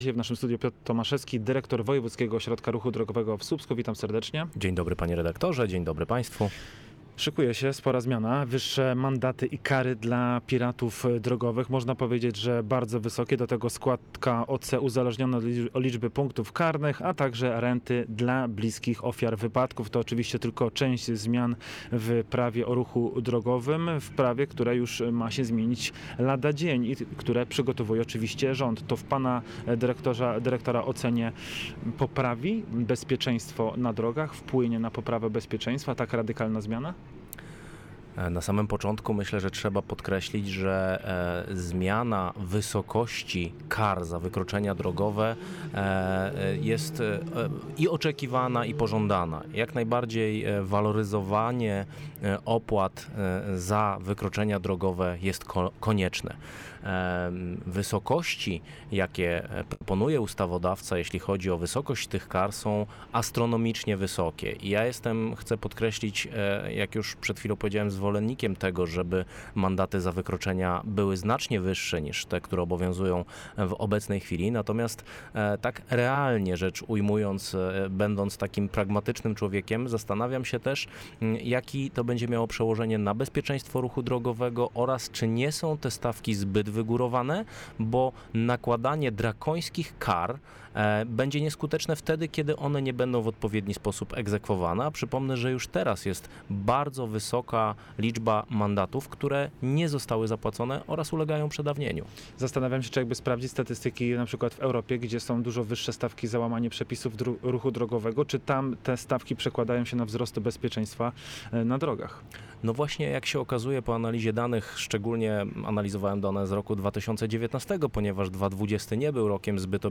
Na antenie programu miejskiego w Słupsku zaznaczał, że nie wiadomo, czy ostatecznie przełoży się na zmniejszenie liczby piratów drogowych.